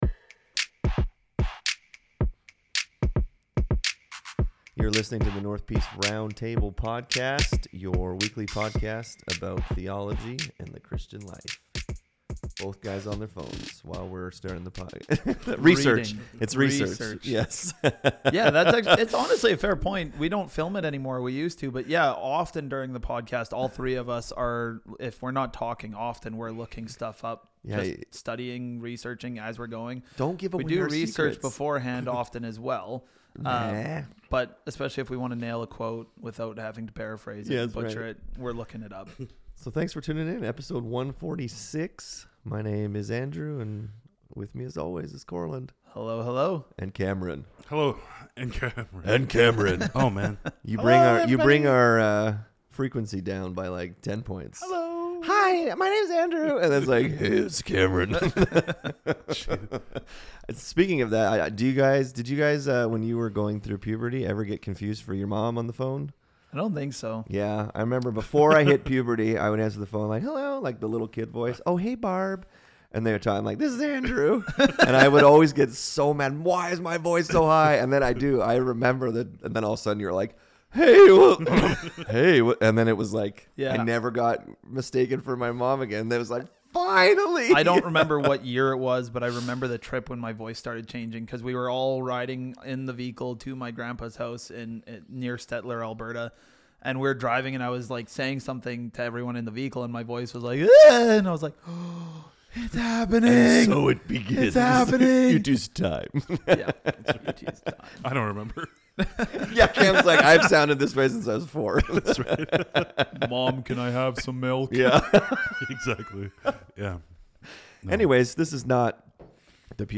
In this episode the guys answer a question about Peter being the rock the church is built on.